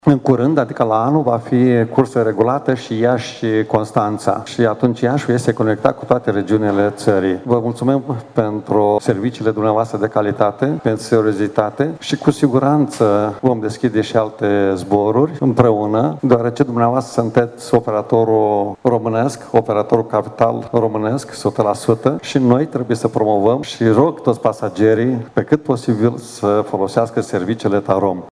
Lansarea noului zbor intern a fost marcată printr-o conferință de presă în zona de plecări a terminalului T3, la care au participat reprezentanți ai companiei Tarom, conducerea Aeroportului Iași și a Consiliului Județean Iași, precum și reprezentanți ai mediului de IT și de afaceri local.